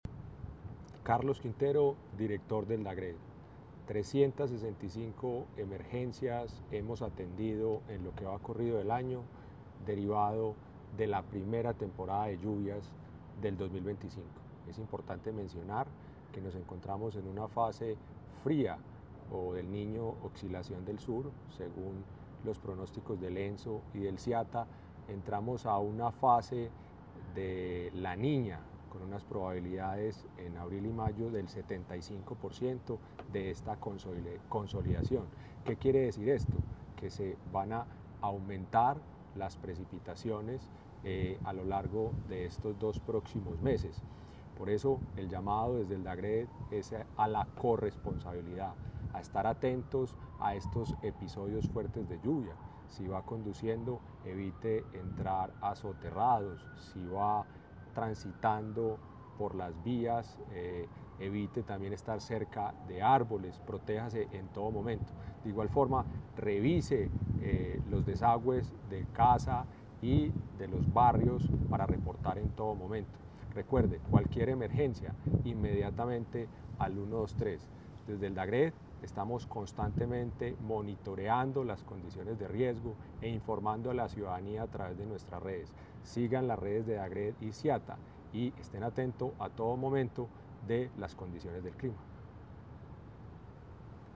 Palabras de Carlos Andrés Quintero, director del Departamento Administrativo de Gestión del Riesgo de Desastres (DAGRD)